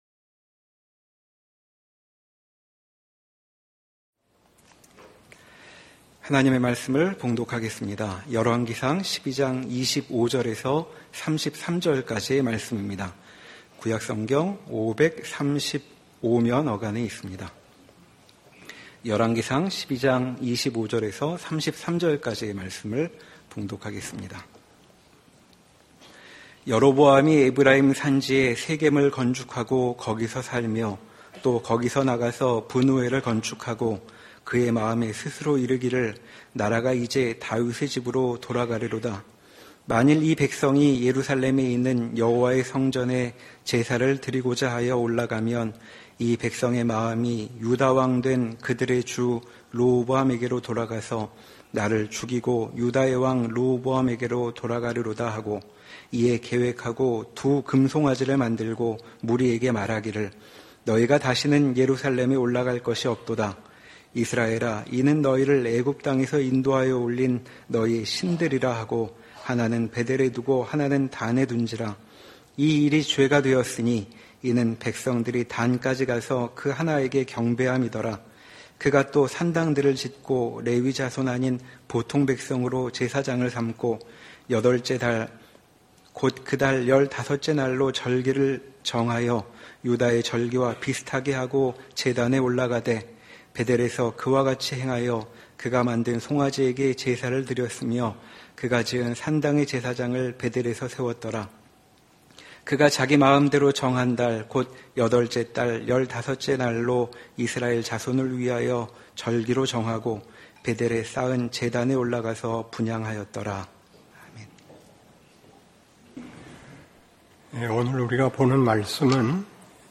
주일예배